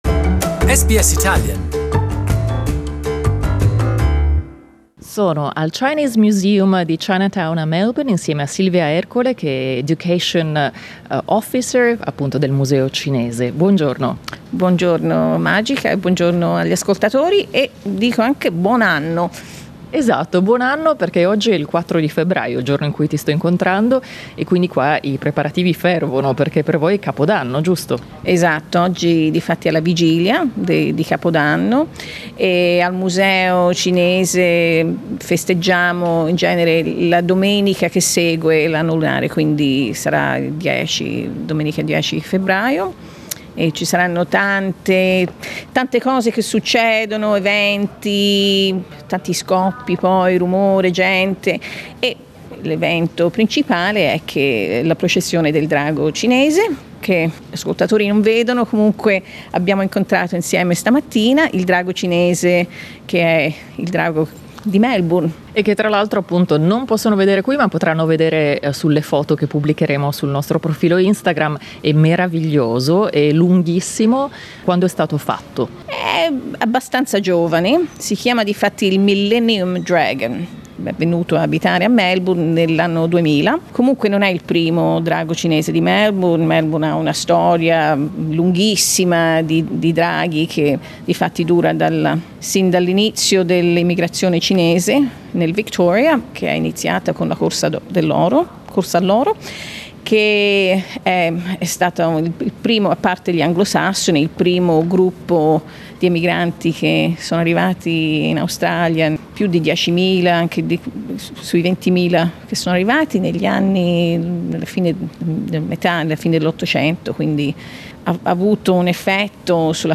Click on the audio player to listen to the interview in Italian.